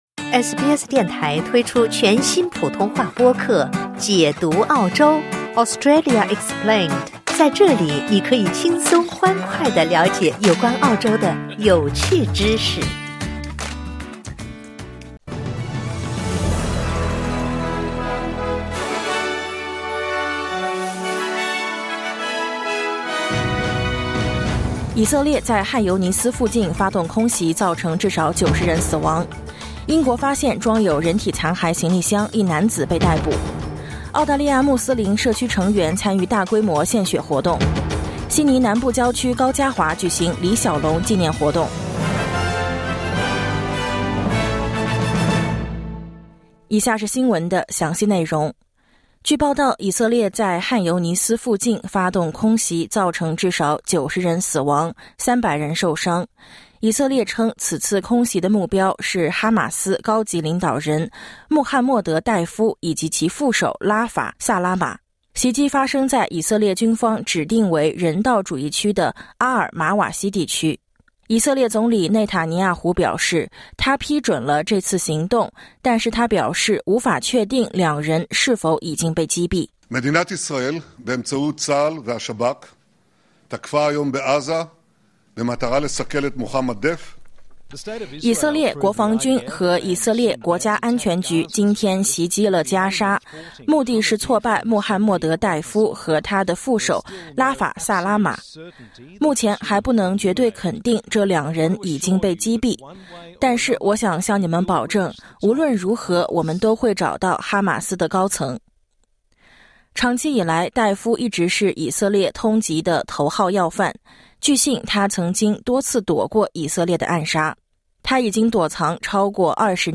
SBS早新闻（2024年7月14日）